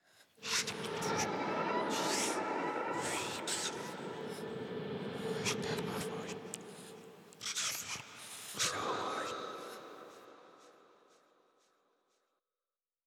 黑雾说话.wav